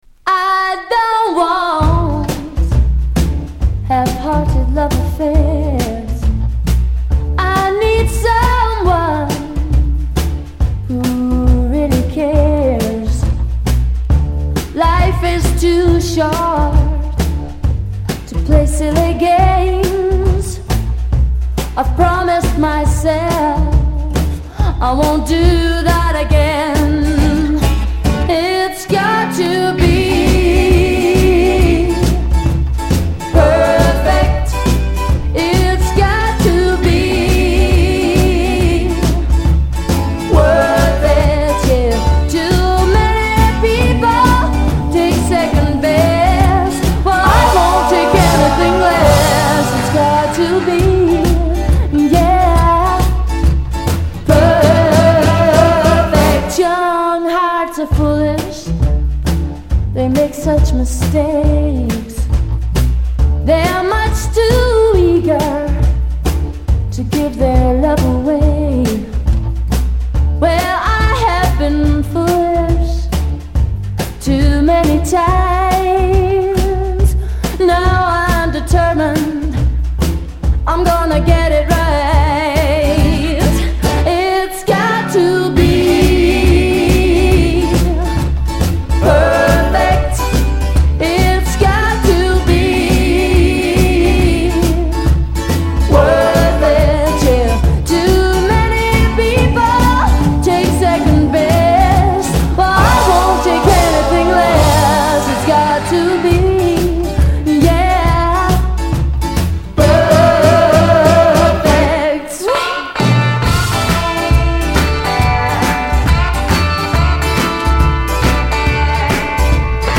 心地よいスウィング感と透明感溢れる柔らかなサウンドに釘付けです。